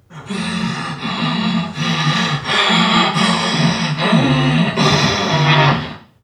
NPC_Creatures_Vocalisations_Robothead [63].wav